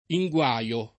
inguaio [ i jgU#L o ]